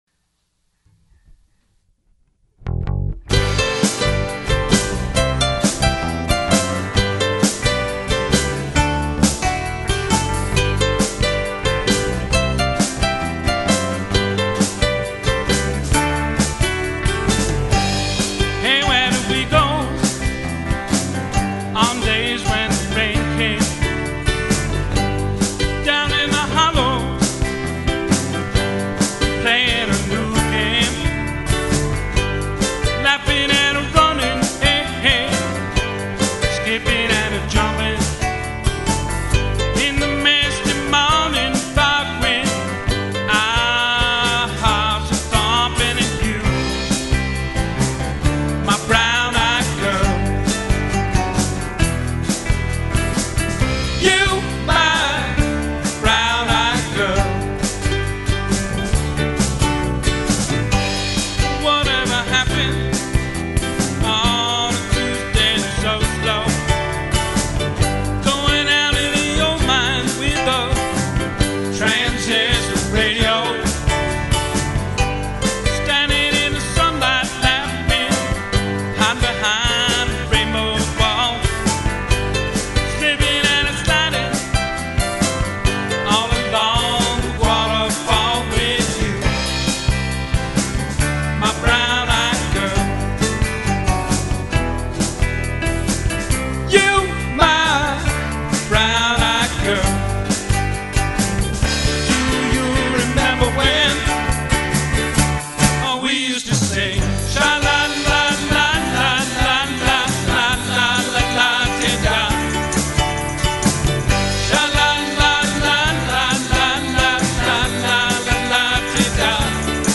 Cover MP3's
Here's a full MP3 recorded from RocknRubner Studio in 2005: